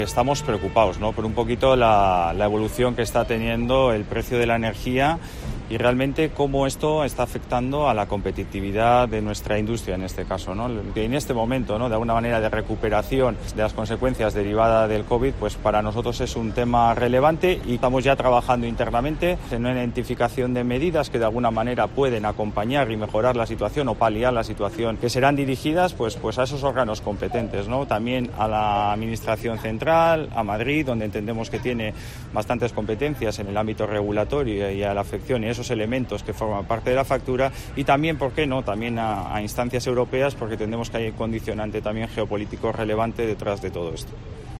Mikel Amundarain, viceconsejero de Industria del Gobierno Vasco